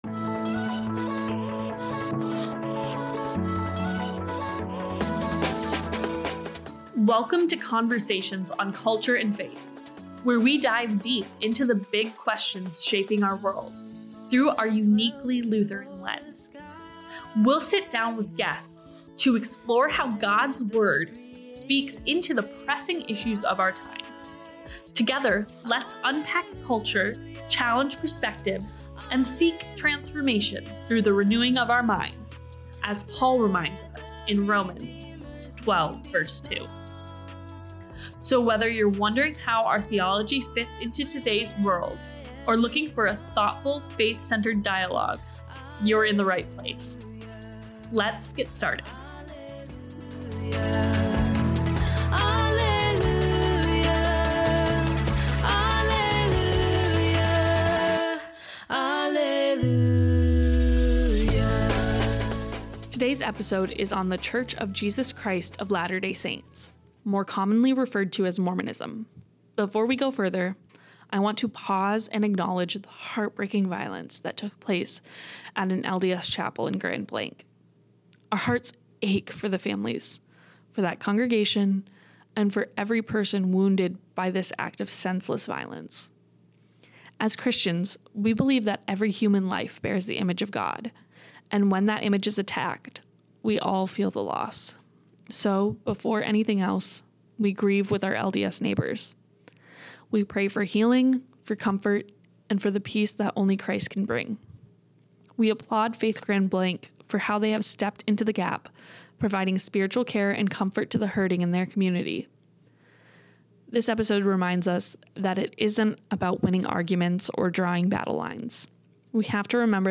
Mormonism v Christianity: hear Lutheran experts discuss LDS teachings on Jesus, salvation, and eternal families, while gaining tools to witness Christ to Mormon friends.